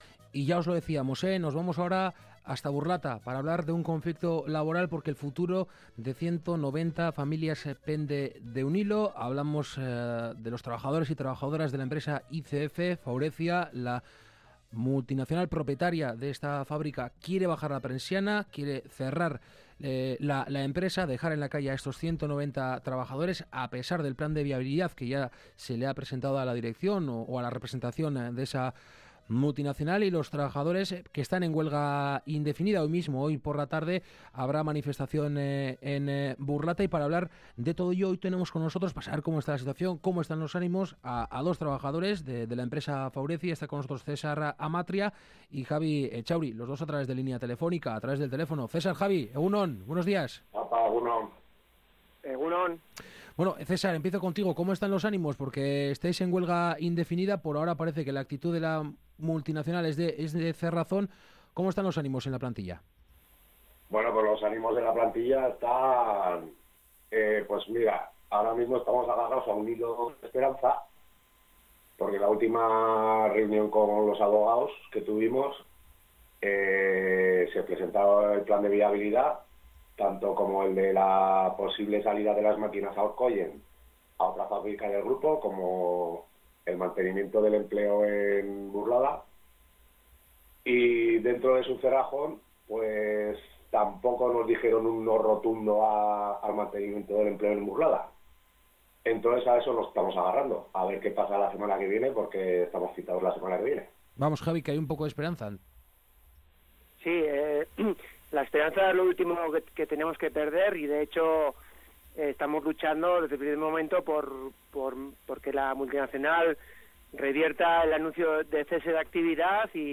Hoy hemos entrevistado a dos trabajadores de ICF-Faurecia